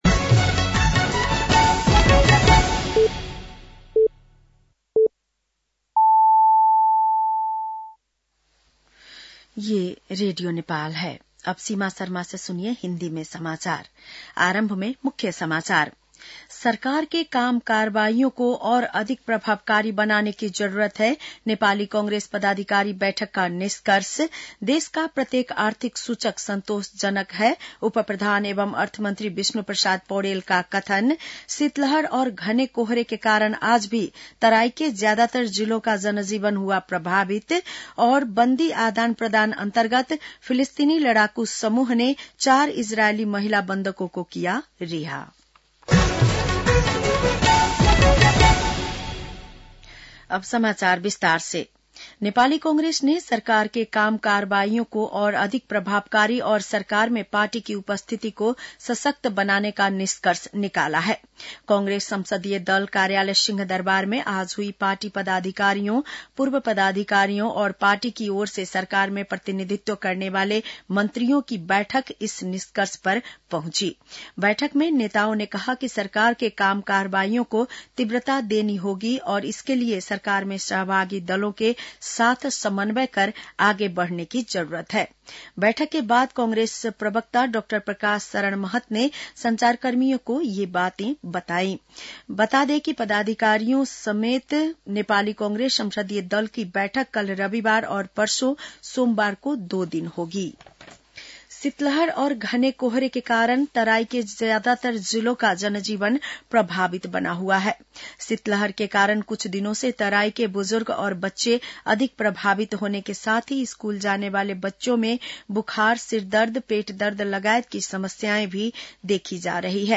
बेलुकी १० बजेको हिन्दी समाचार : १३ माघ , २०८१